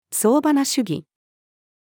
総花主義-female.mp3